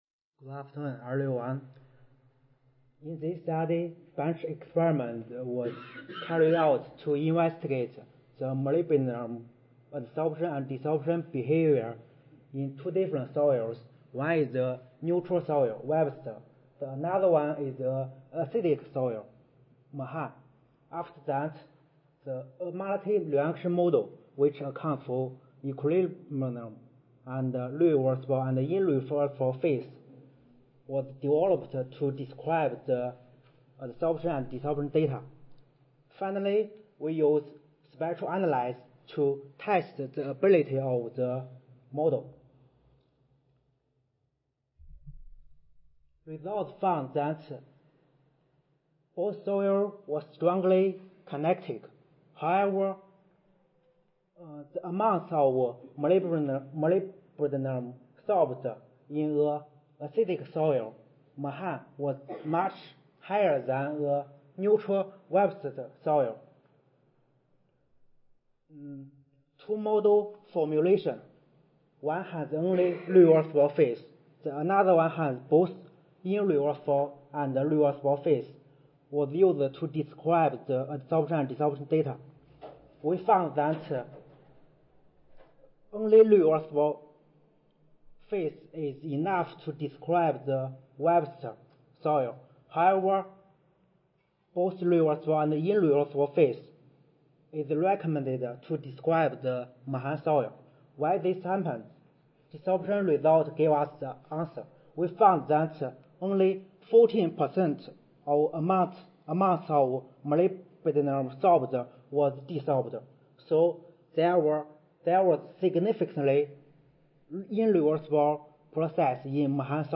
Audio File Recorded Presentation